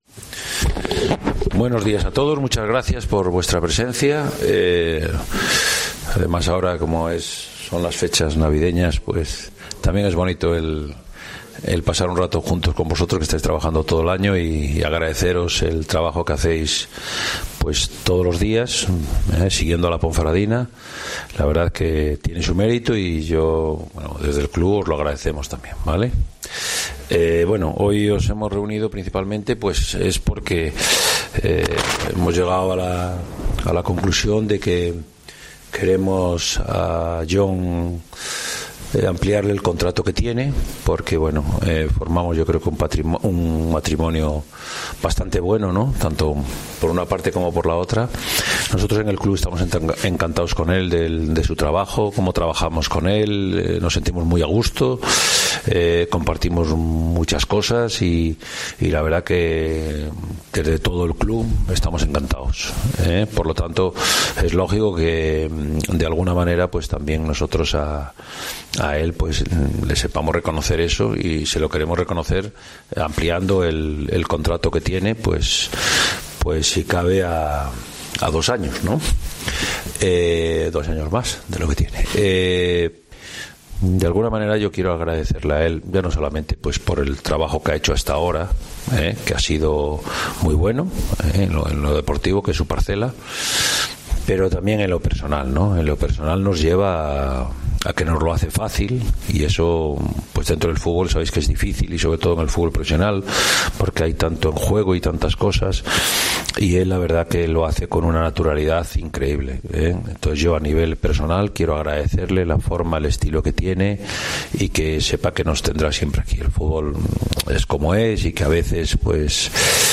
La noticia la ha dado a conocer en un una rueda de prensa